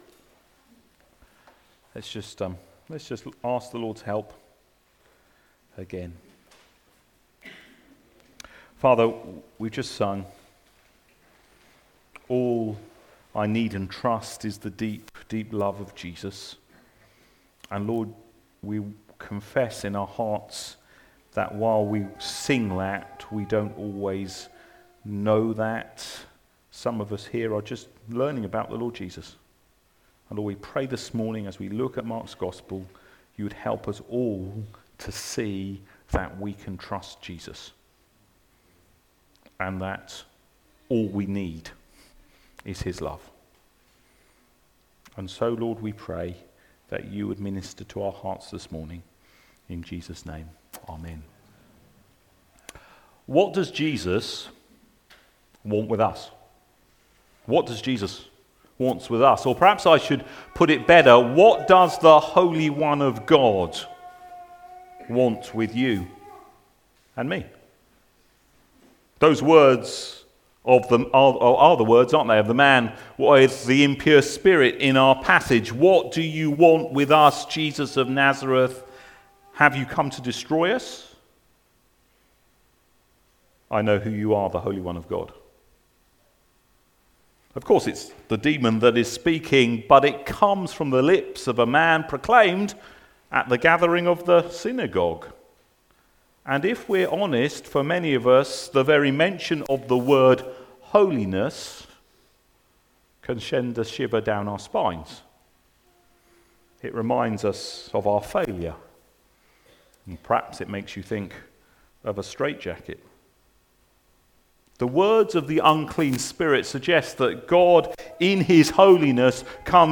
LABC Sermons Mark 1v21-28 The Holy One brings freedom Play Episode Pause Episode Mute/Unmute Episode Rewind 10 Seconds 1x Fast Forward 30 seconds 00:00 / 37:14 Subscribe Share RSS Feed Share Link Embed